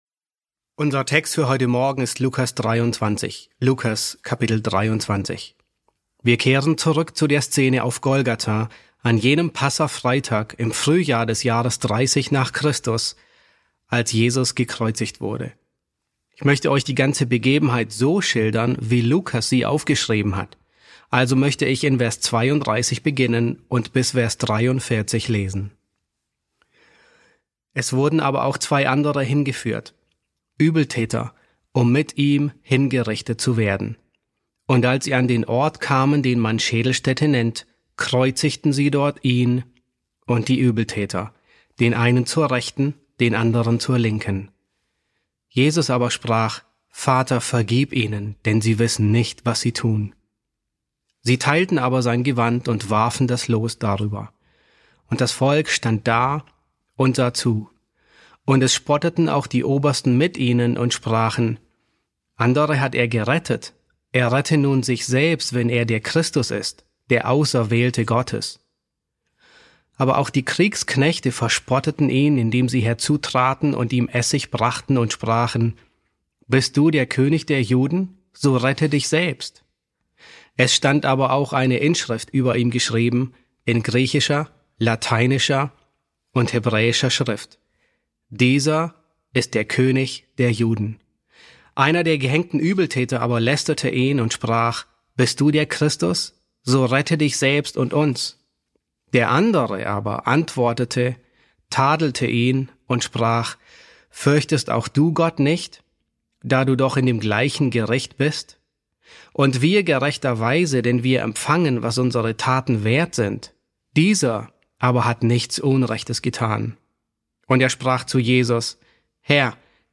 E3 S1 | Der gekreuzigte König: Bekehrung auf Golgatha ~ John MacArthur Predigten auf Deutsch Podcast